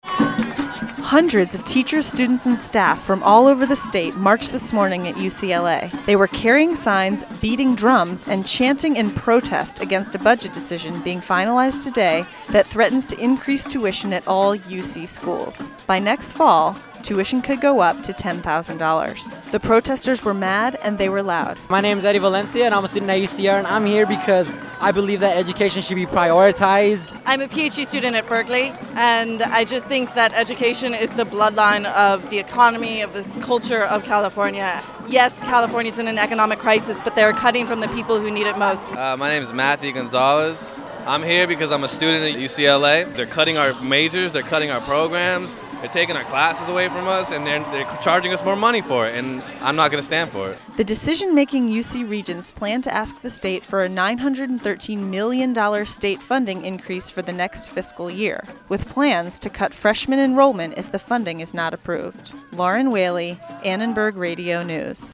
Thousands of students protested tuition increases outside the UC Board of Regents meeting at UCLA.
After it was announced they had approved the increase, protesters surrounded the building, blocked roads and chased down anyone that tried to leave. Riot police stood guard around the building as students chanted and screamed.
uclaprotest1.mp3